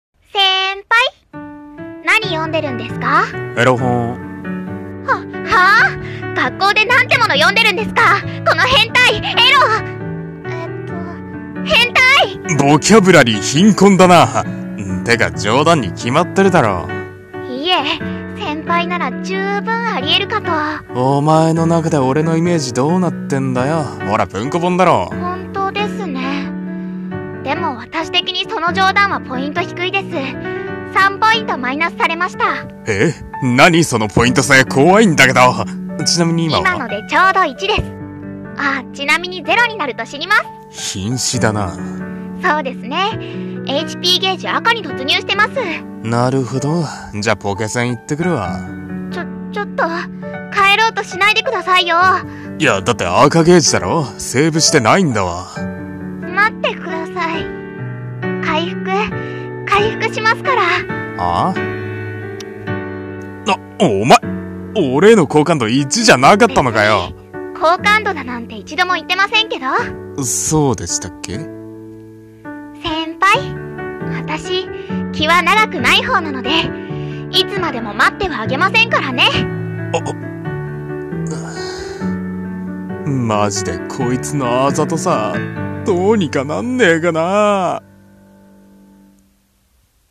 【二人声劇】回復しますから（コラボ募集）